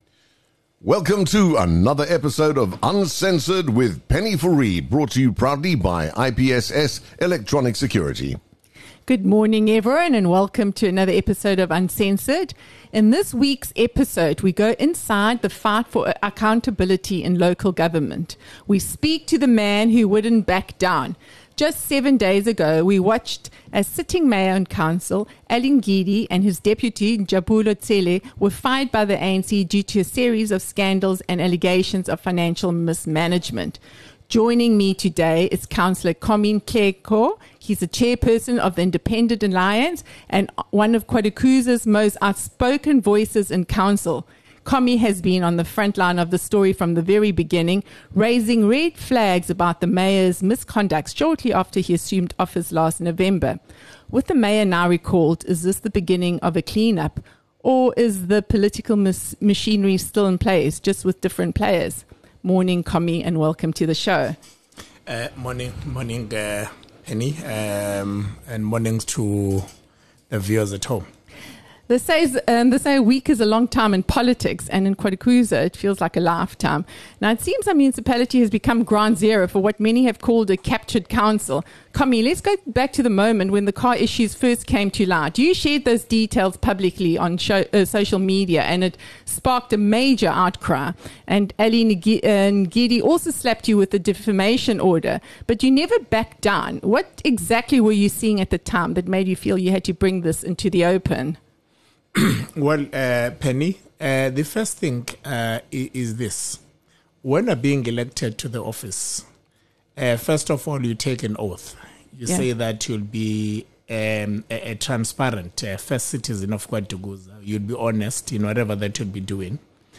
We speak to Councillor Commie Nhtleko, Chairperson of the Independent Alliance and one of the earliest voices to challenge the now-recalled mayor and his deputy. From leaked documents to political retaliation, Nhtleko walks us through the warning signs and how power was abused from the start.